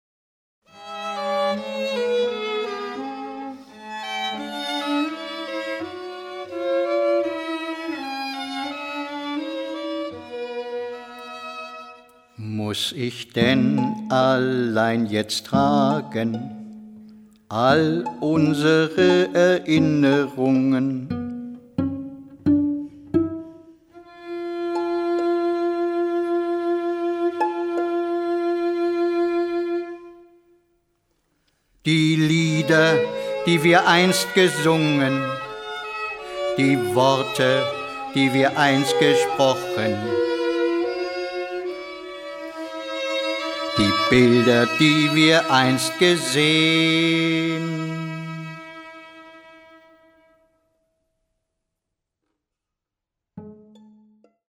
Klavier
Geige
Cello
Harfe
Trompete
Hammondorgel
Schlagzeug
Chor